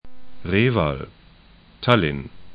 Reval 're:val Tallinn 'talɪn et [hist.]